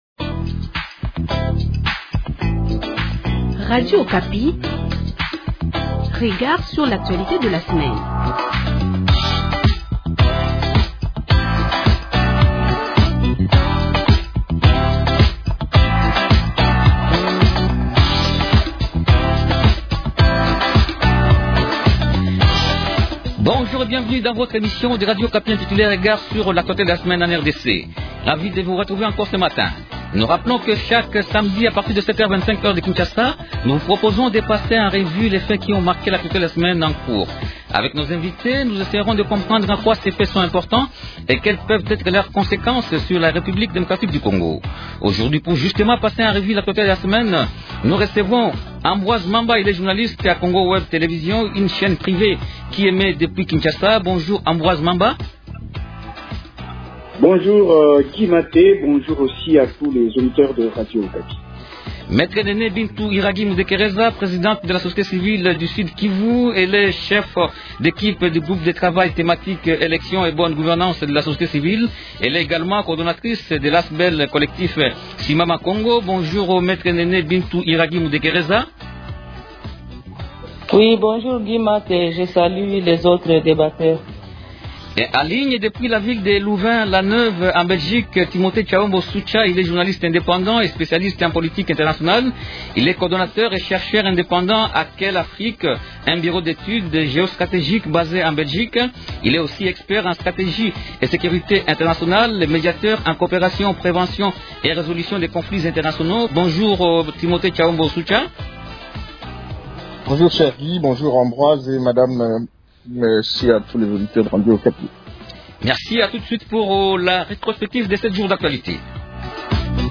Trois sujets principaux au menu de cette émission :